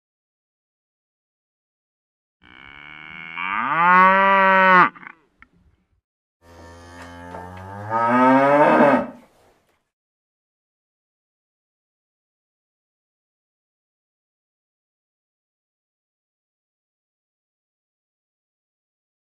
دانلود آهنگ مو مو گاو 1 از افکت صوتی انسان و موجودات زنده
جلوه های صوتی
دانلود صدای مو مو گاو 1 از ساعد نیوز با لینک مستقیم و کیفیت بالا